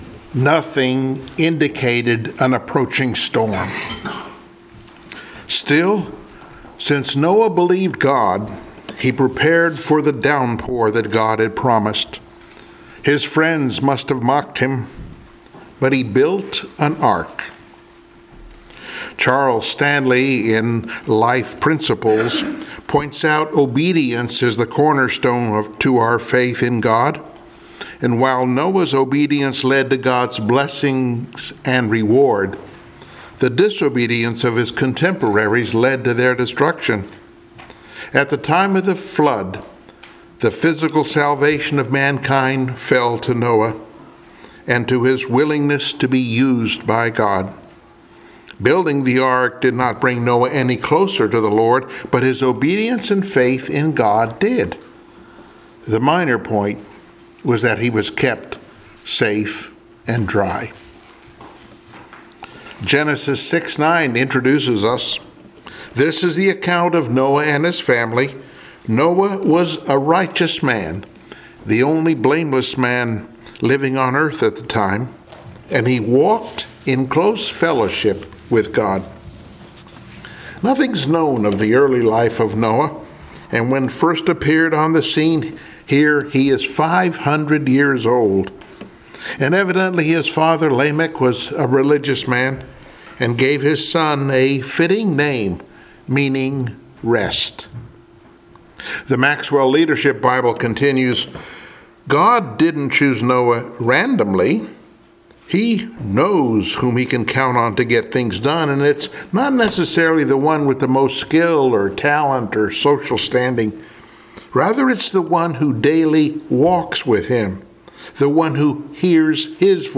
Cast of Characters Passage: Genesis 8:6-11 Service Type: Sunday Morning Worship « “Jabez